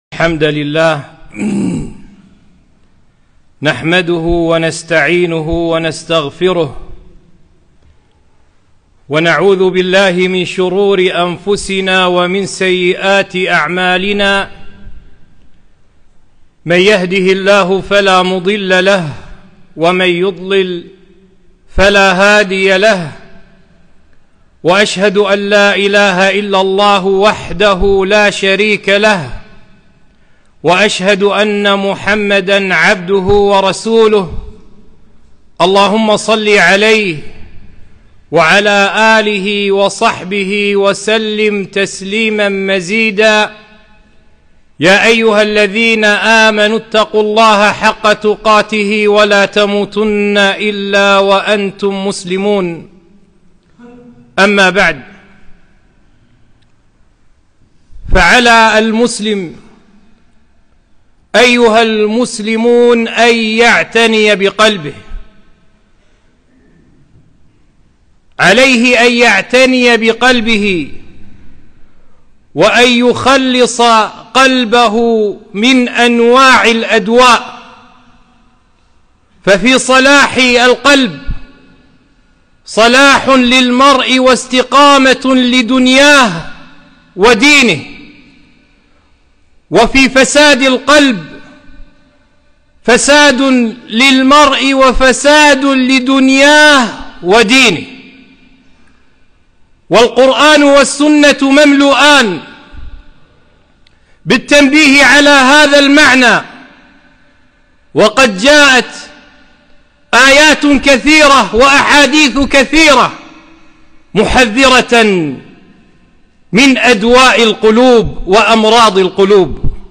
خطبة - ذم إعجاب المرء نفسه